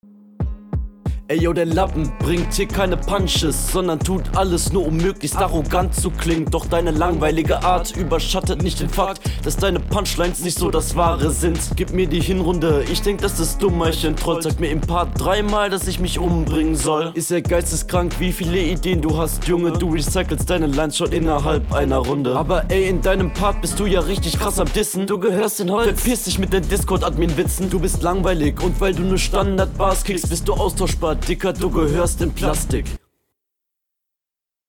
Punches sehr schwach und Flow auch echt mies.